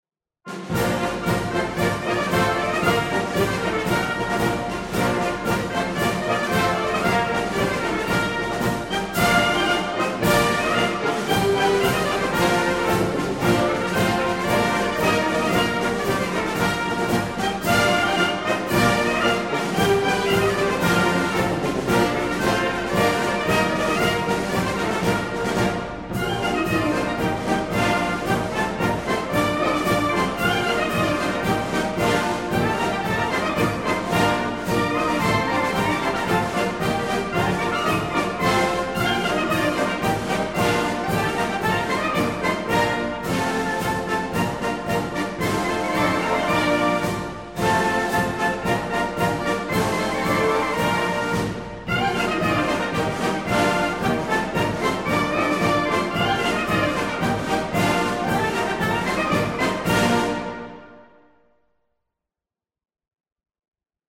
Quick March: